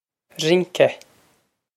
rince rin-ke
This is an approximate phonetic pronunciation of the phrase.